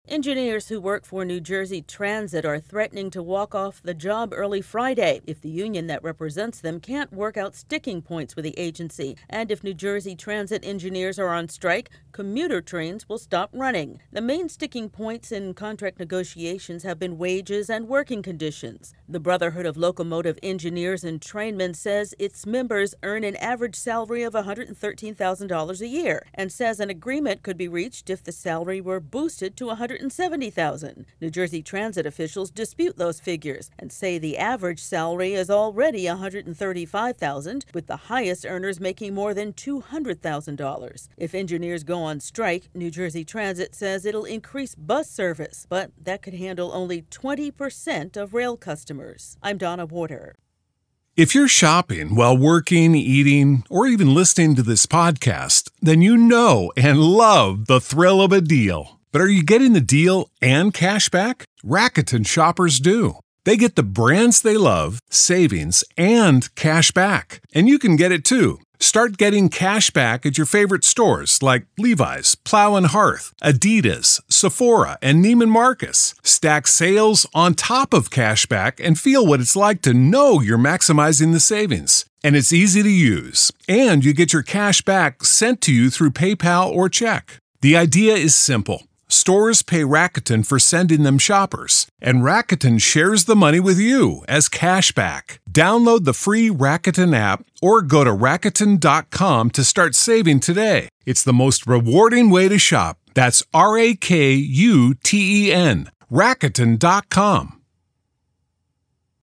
Some New Jersey commuters could be stranded or stuck home with no transportation early Friday. AP correspondent